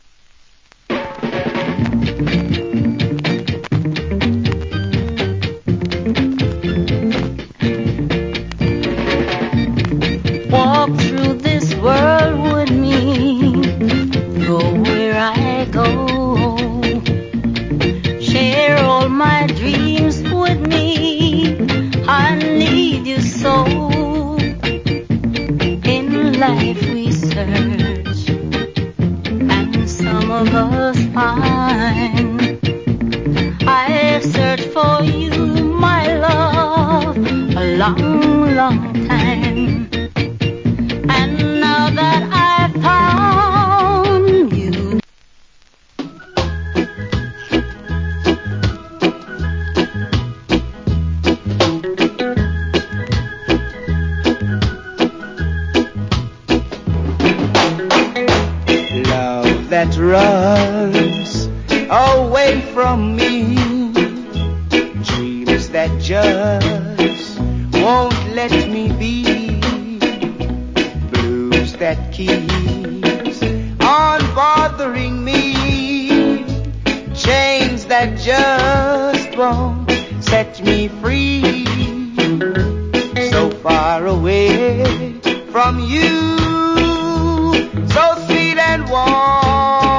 Nice Early Reggae.